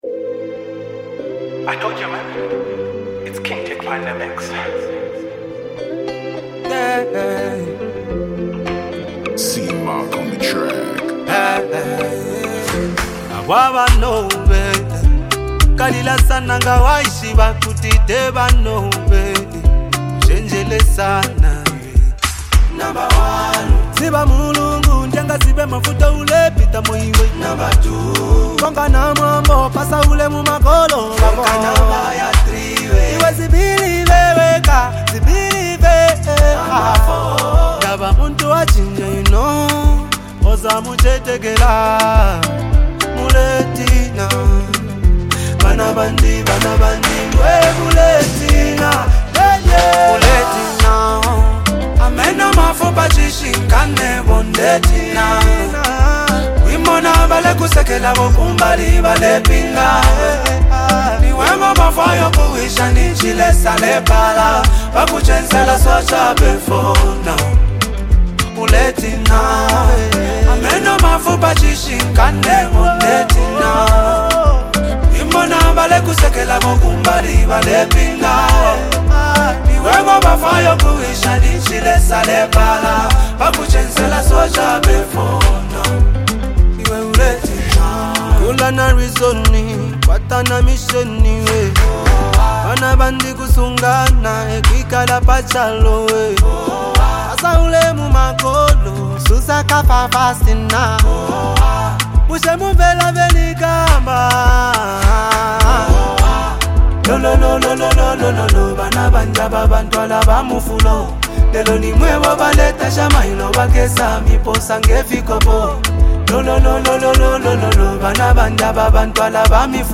blending soothing melodies with deep lyrical expression.
The song carries a strong emotional weight
fusing soft instrumentals